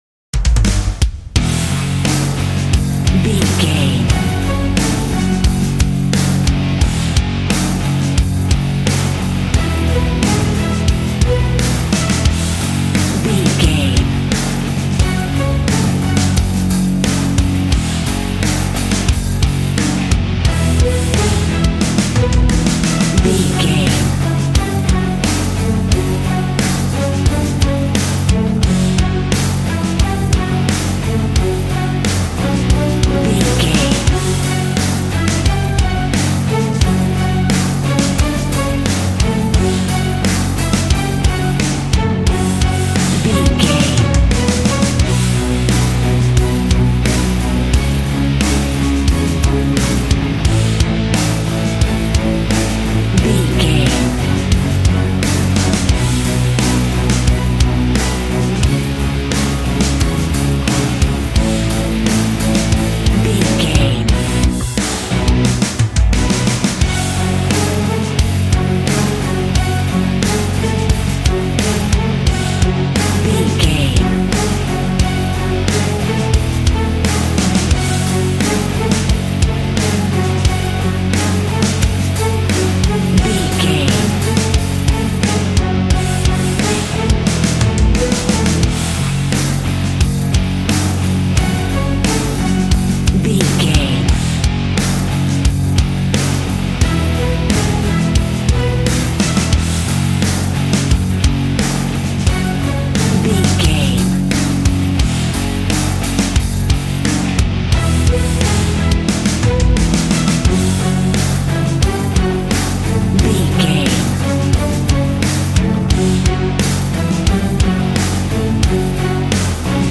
Aeolian/Minor
G♭
angry
aggressive
electric guitar
drums
bass guitar